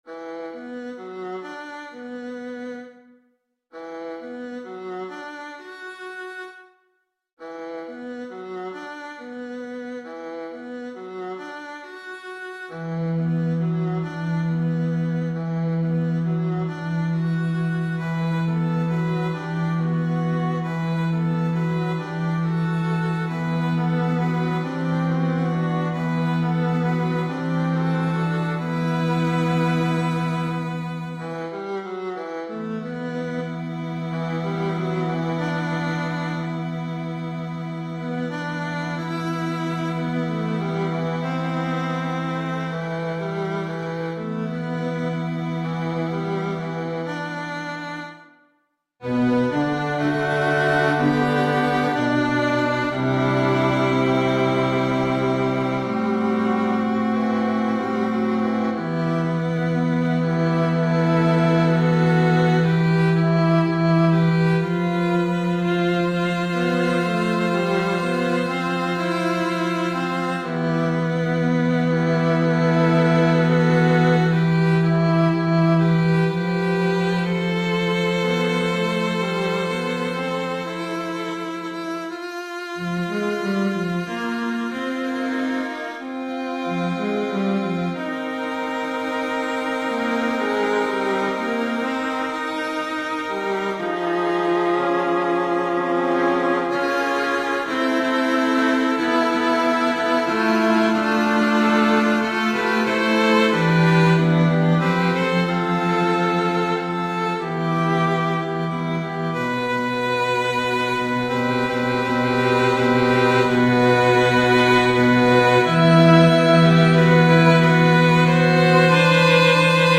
SATB
MP3 (instrumental)